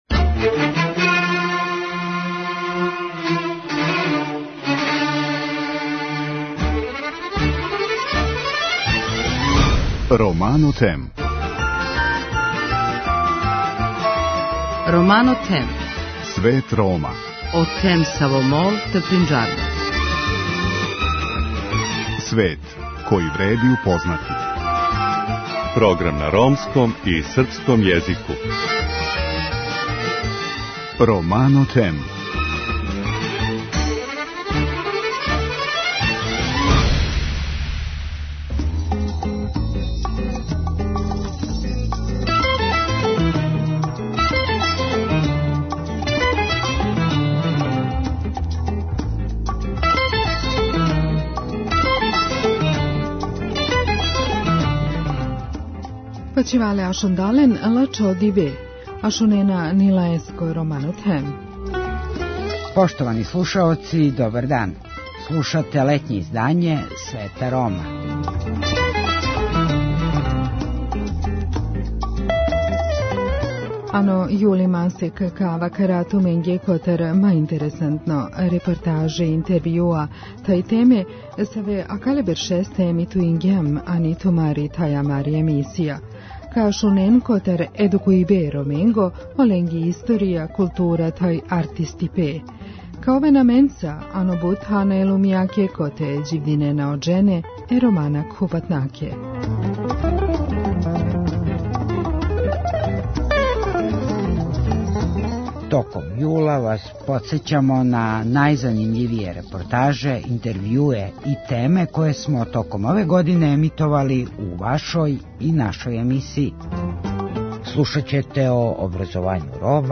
У летњем издању Света Рома ови млади људи говоре о искуствима које су понели са те омладинске размене.